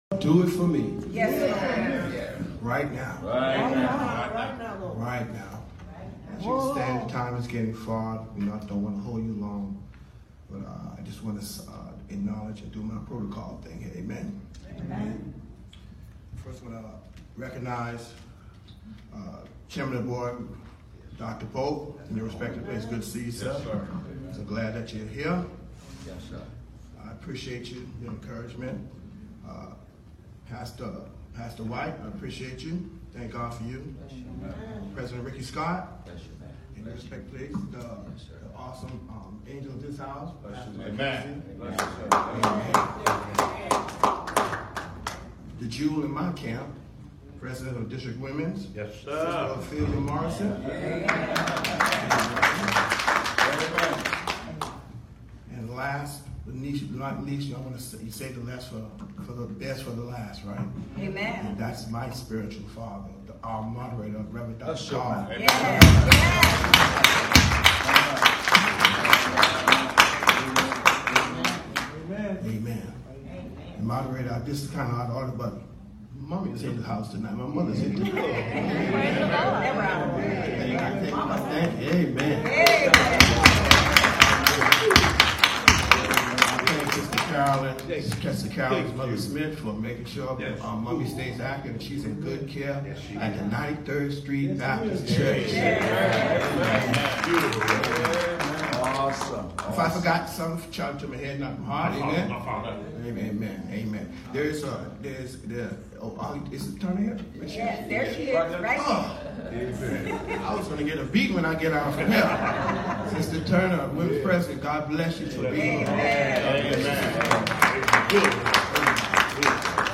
JUNE 28, 2019 FRIDAY 7:00pm AT MT NEBO MB Church, SEABOARD BAPTIST ASSOCIATION, MINISTERS AND DEACONS UNIY